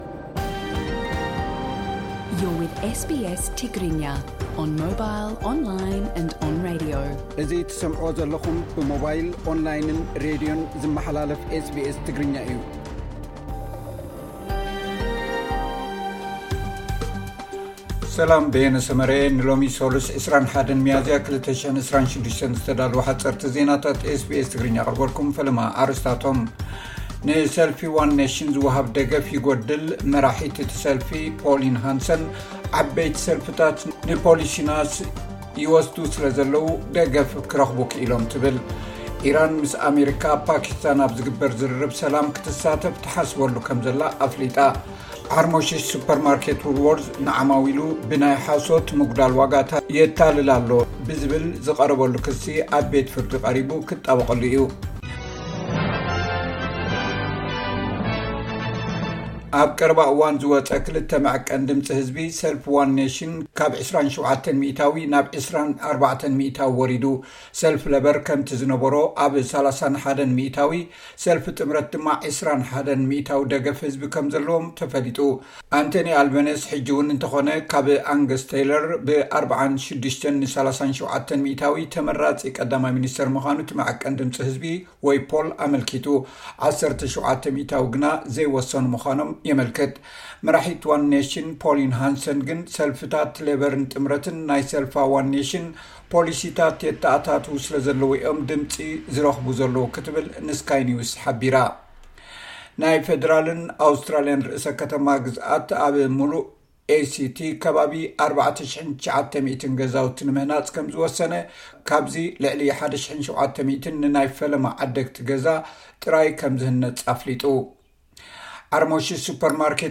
SBS Tigrinya Newsflash